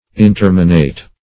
Interminate \In*ter"mi*nate\, a. [L. interminatus; in- not +